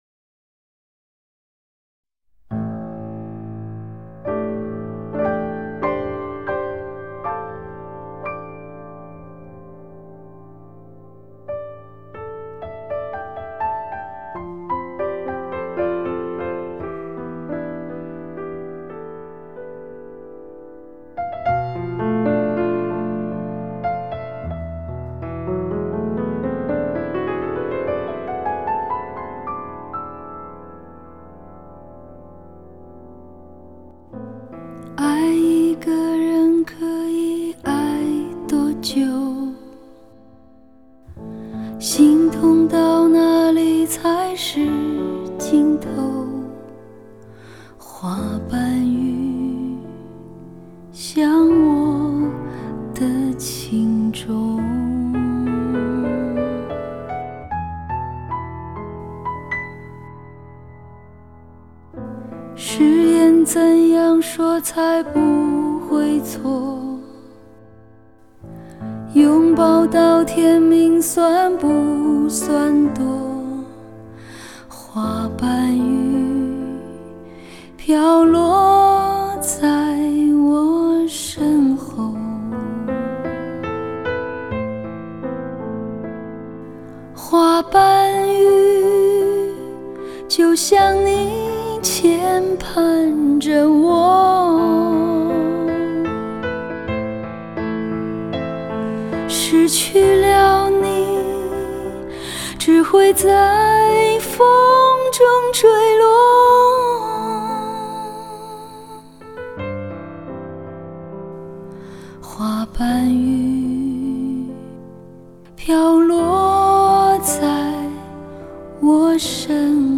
深厚的演唱功底 充满情感的声音媚力 极度磁性的嗓音特质